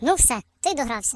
project_files/HedgewarsMobile/Audio/Sounds/voices/Default_uk/Gonnagetyou.ogg
Gonnagetyou.ogg